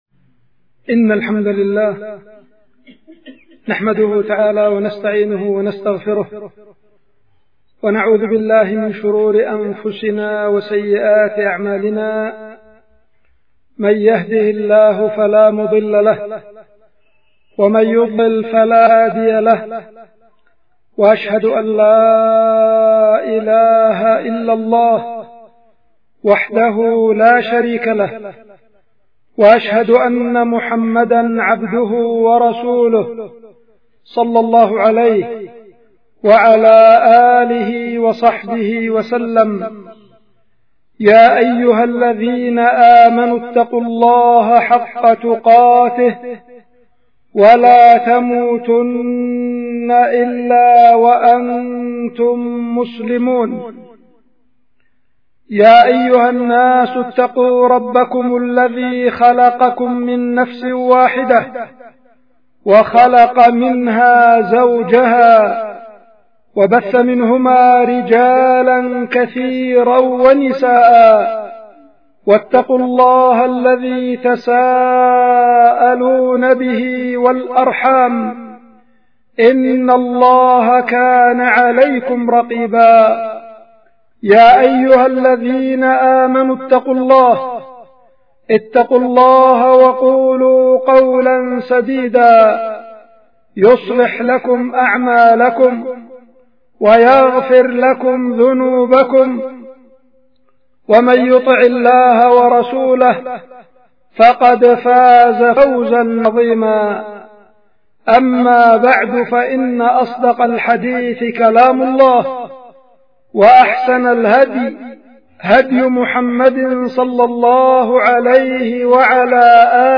القيت في الجامع الكبير في العزلة- بعدان – إب-اليمن
خطبة